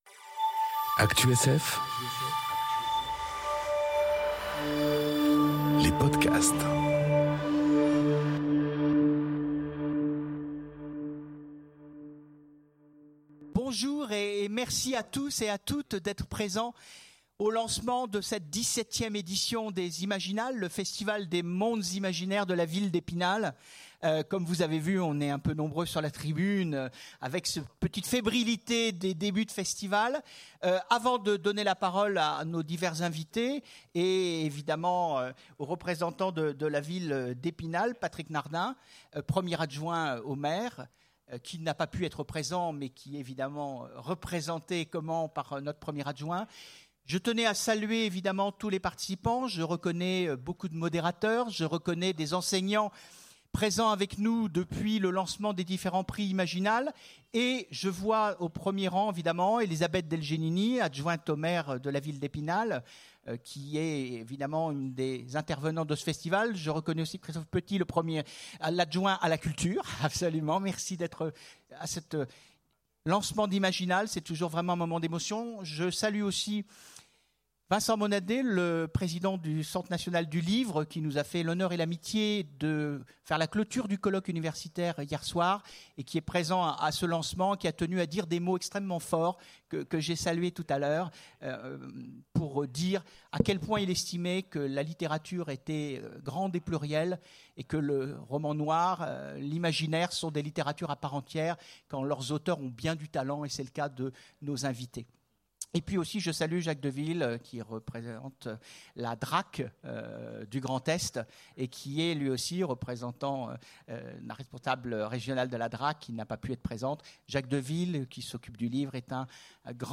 Conférence Le coup d'envoi des Imaginales 2018